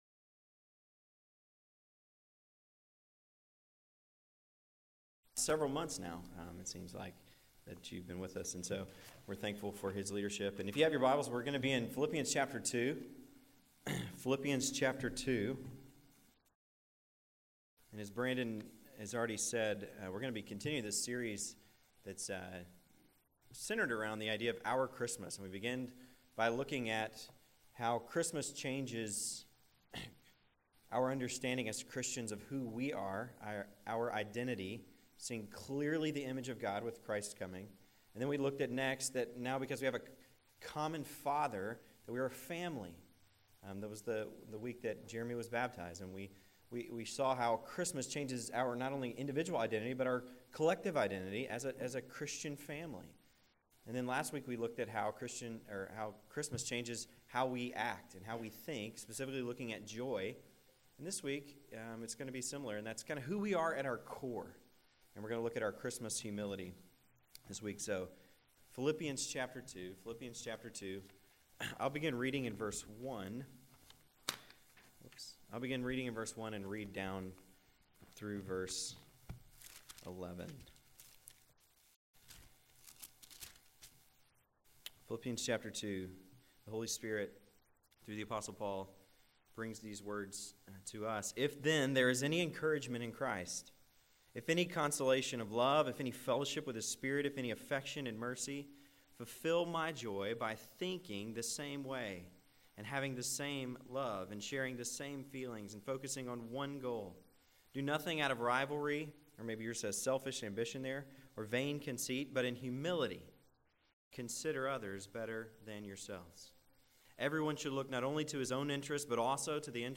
This is the fourth message in an Advent sermon series.